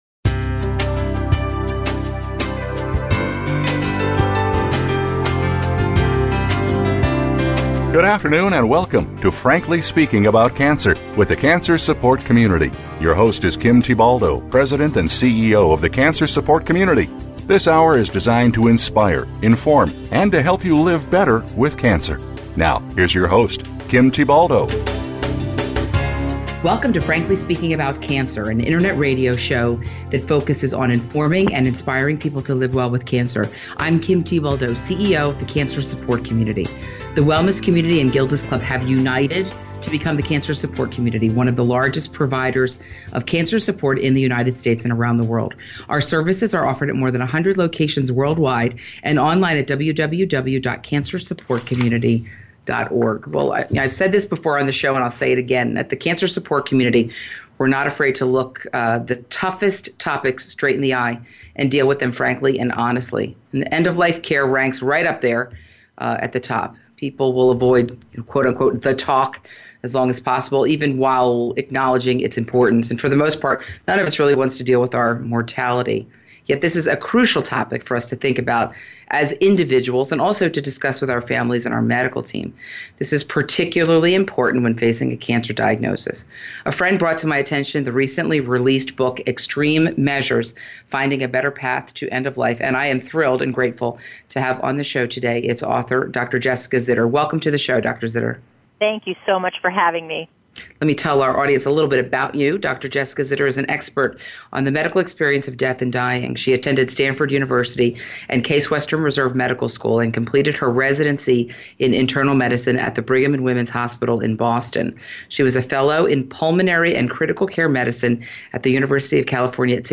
On Your Terms: A Conversation About End-of-Life Care